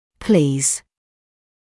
[pliːz][pliːz]радовать, доставлять удовольствие; удовлетворять; пожалуйста